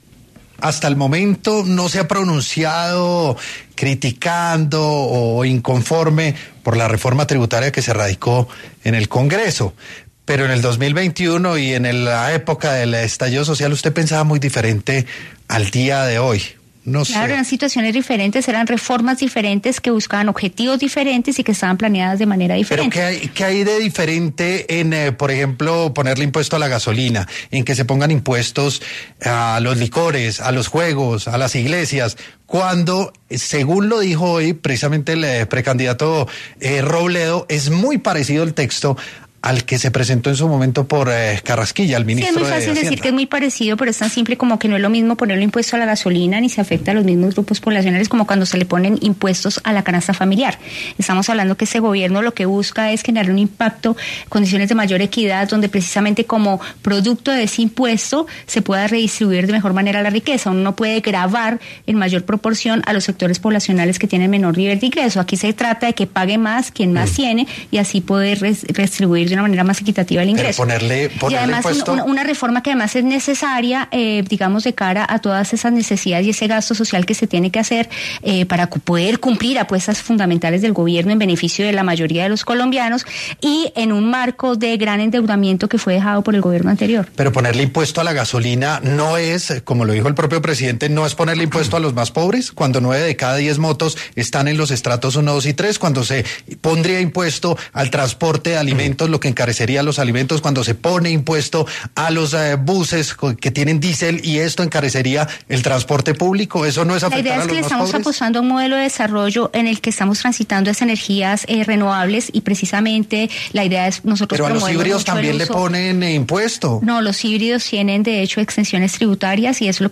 En Sin Anestesia de La Luciérnaga, estuvo Cielo Rusinque, superintendente de Industria y Comercio, quien habló sobre el impacto de la reforma tributaria en Colombia